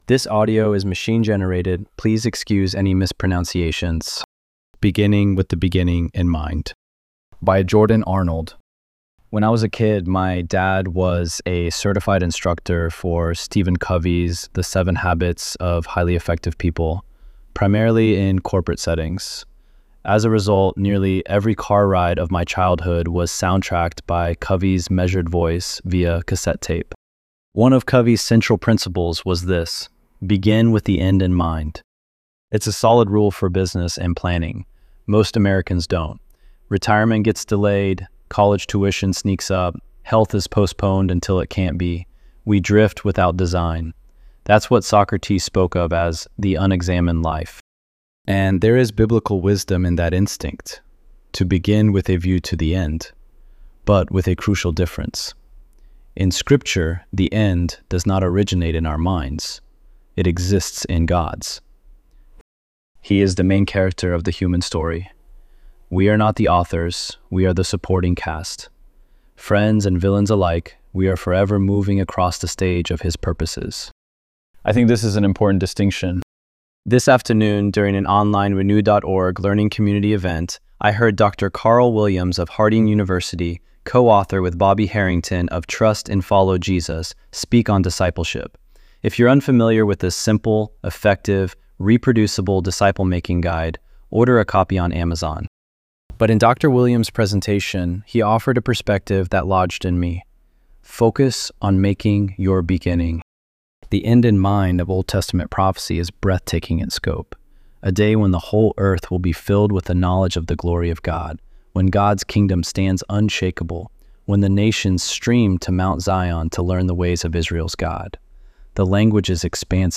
ElevenLabs_2_2-2.mp3